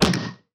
add SFX
hit-v1.ogg